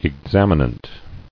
[ex·am·i·nant]